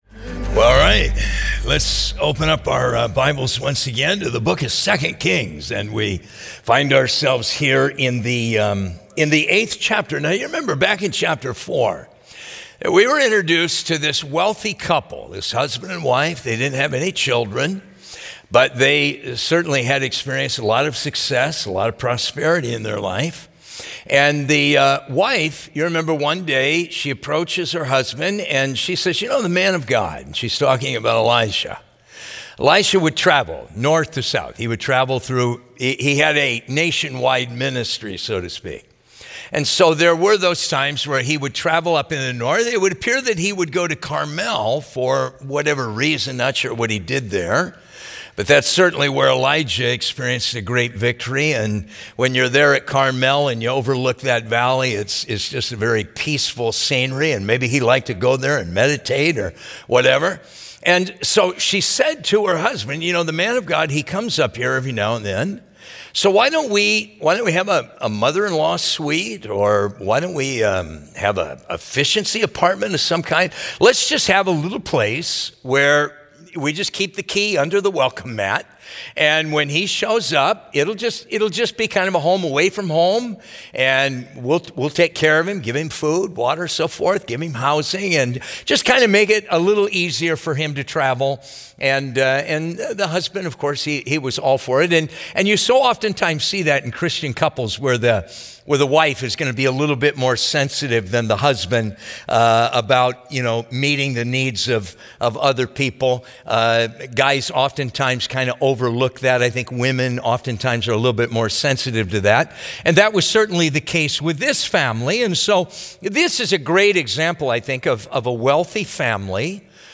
Teachings